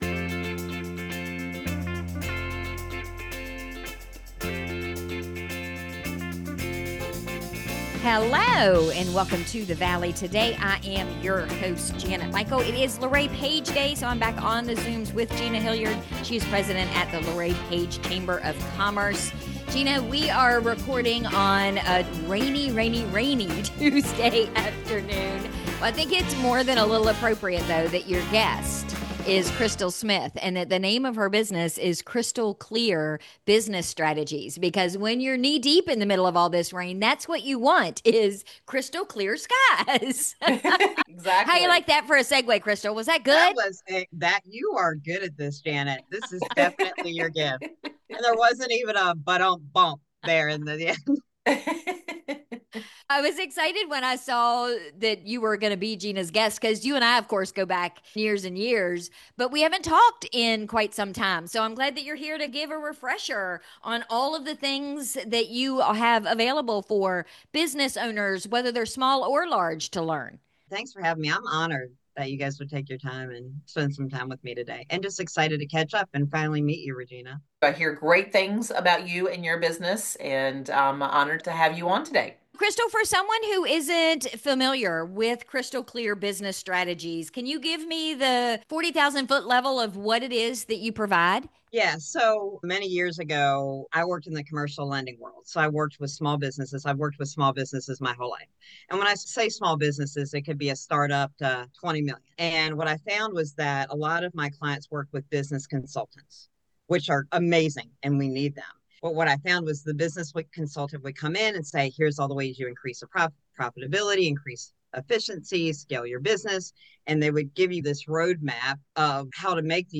The-River-Interview-2.mp3